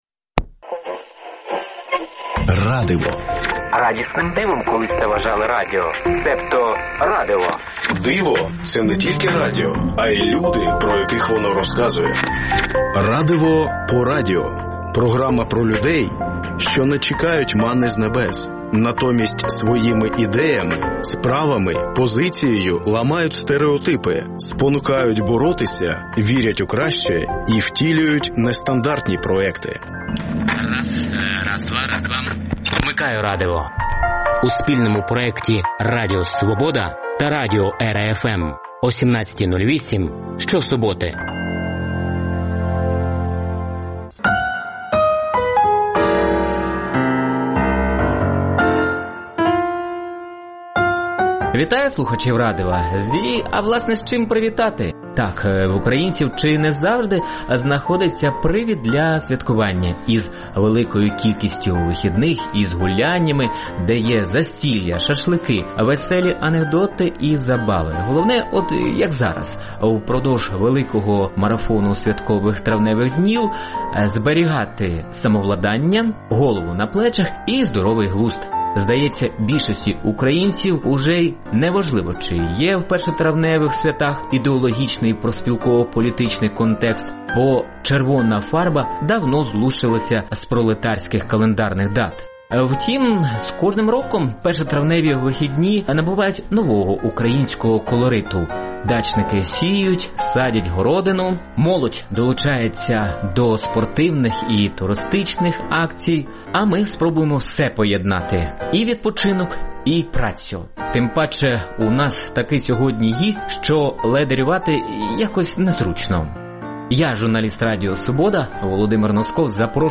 «Радиво» по радіо – це спільний проект Радіо Свобода та радіо «ЕРА-Fm» що присвячується людям, які не чекають манни з небес, натомість своїми ідеями, справами, позицією ламають стериотипи, спонукають боротися, вірять у краще і втілюють нестандартні проекти. Вмикайте «Радиво» що-суботи О 17.08. В передачі звучатимуть інтерв’ю портретні та радіо замальовки про волонтерів, військових, вчителів, медиків, громадських активістів, переселенців, людей з особливими потребами тощо.